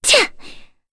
Kirze-Vox_Attack2_kr.wav